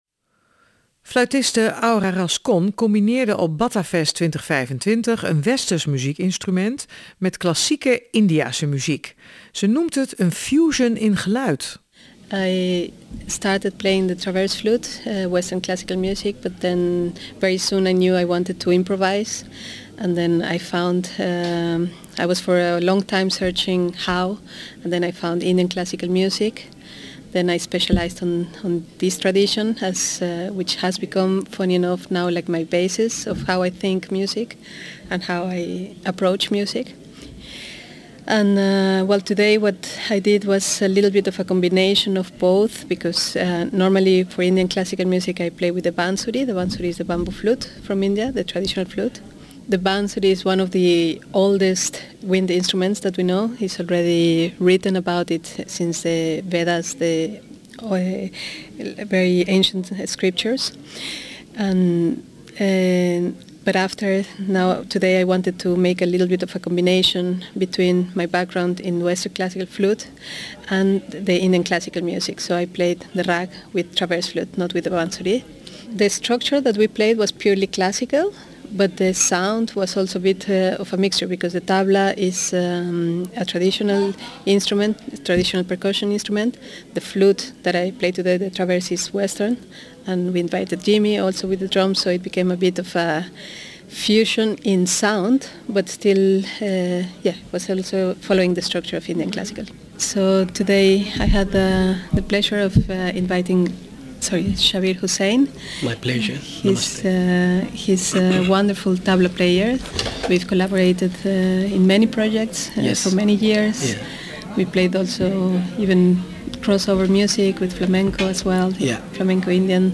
In deze aflevering nemen we je opnieuw mee naar het Batavierhuis in Rotterdam, voor het tweede deel van de opnames die we tijdens Batafest 2025 in oktober gemaakt hebben.